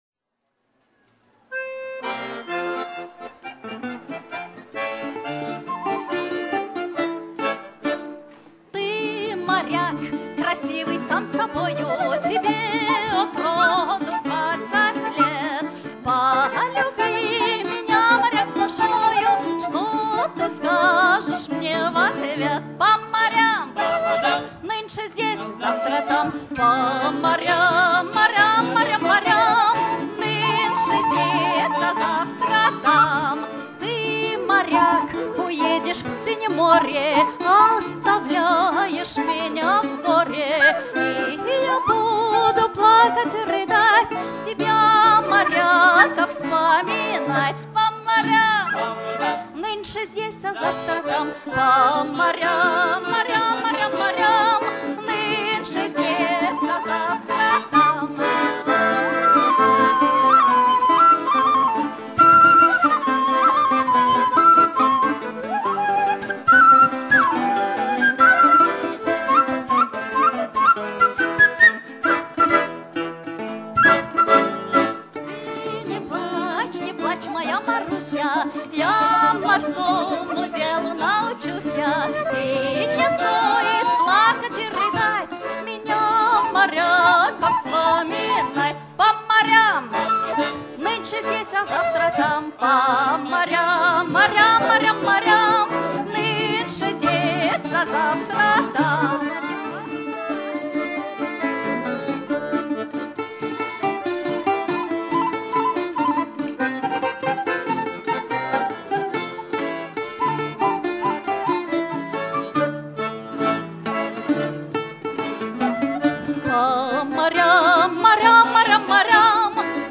chant contemporain apprécié des marins